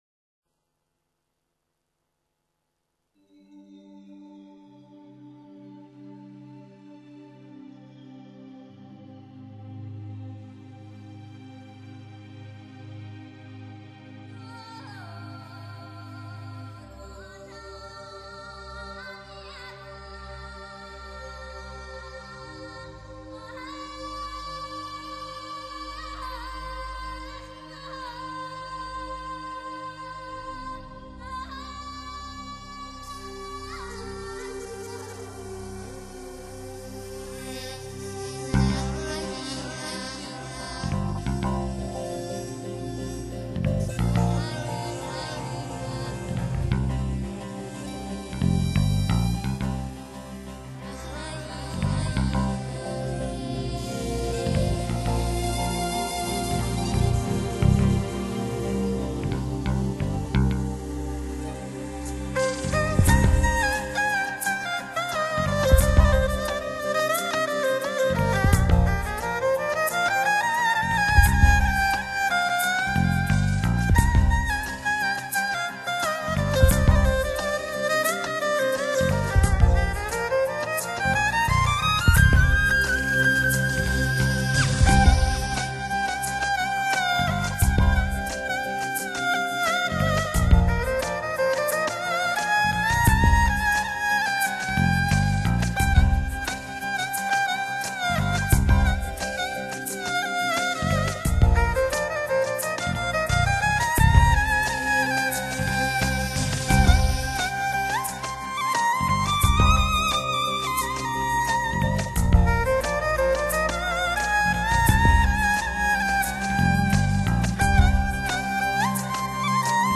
试音碟
美演绎HDCD